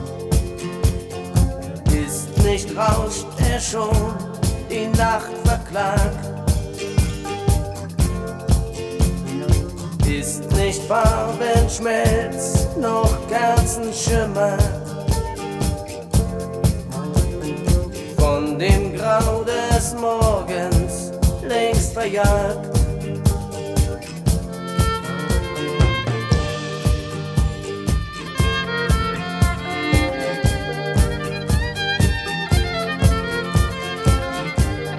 Жанр: Поп музыка / Рок